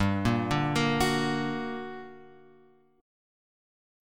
G Minor Major 7th